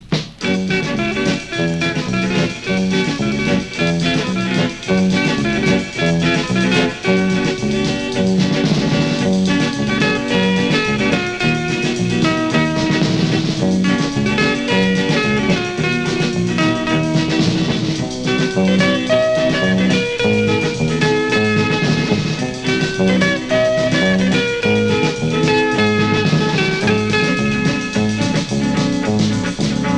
Rock instrumental Unique EP retour à l'accueil